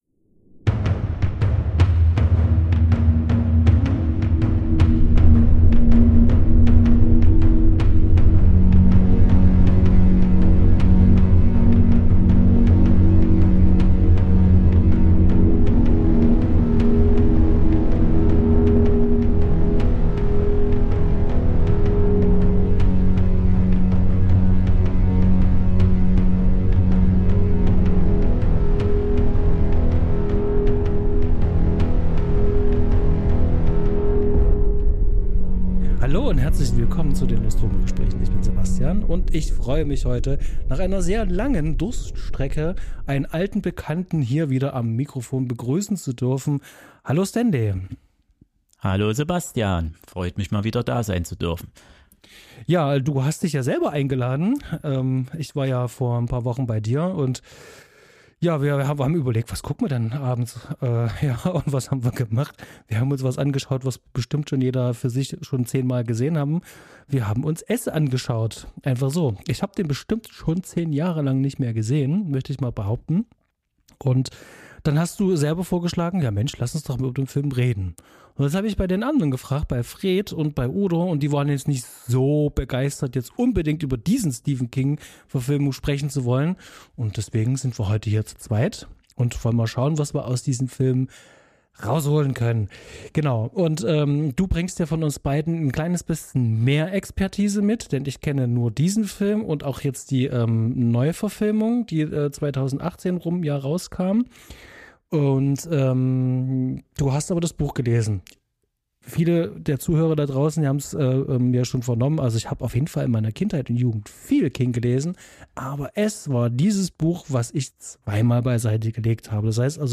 mal im Duo, mal im Trio und ab und an mit erlesenen GästInnen über ihre große Leidenschaft - Filme. Der Schwerpunkt der cineastischen Auseinandersetzungen liegt zum einen auf formalen Qualitäten, wie der Inszenierung, der Produktion und Herstellung, darüber hinaus auf technischen Aspekten, wie Kamera, Lichtsetzung, Schnitt und Colorgrading, und zu guter Letzt auf der inhaltlichen Interpretation der Stoffe.